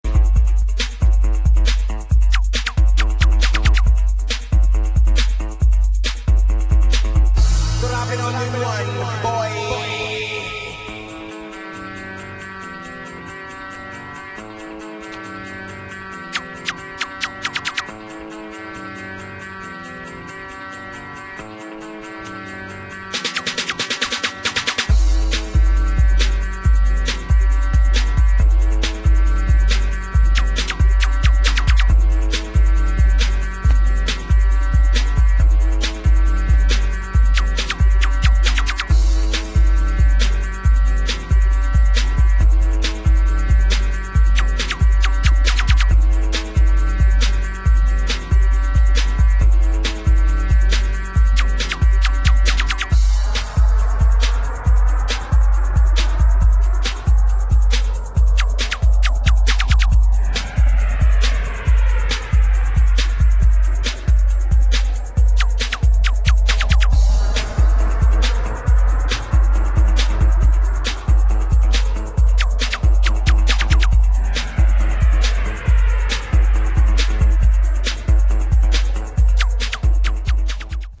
[ BREAKS / BREAKBEAT ]